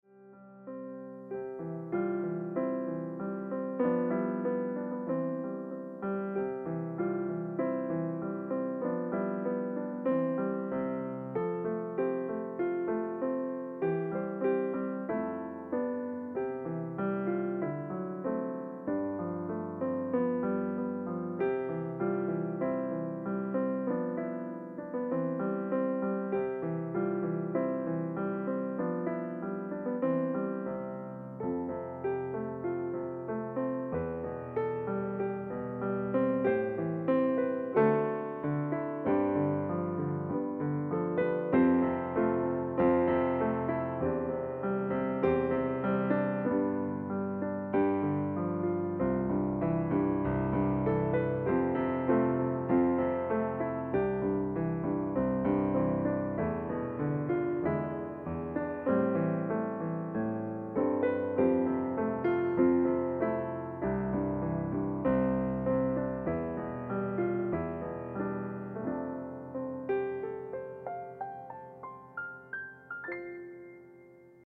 Your pianist for every event